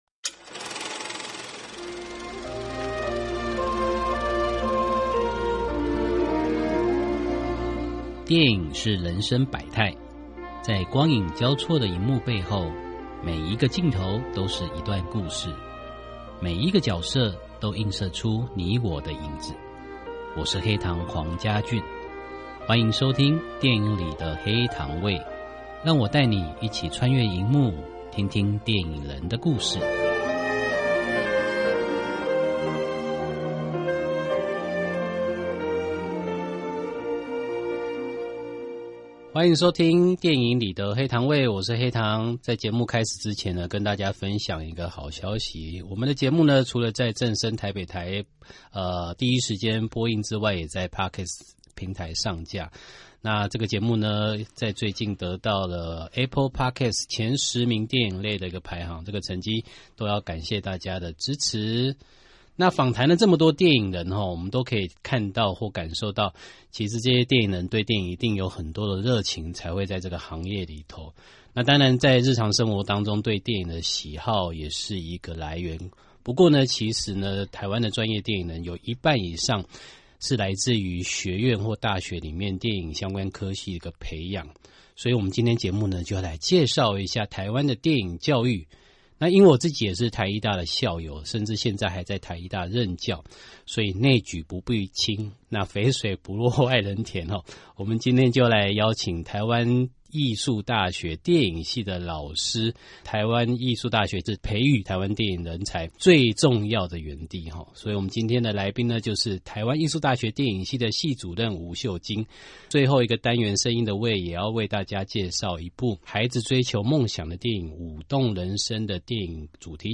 訪問大綱： 1.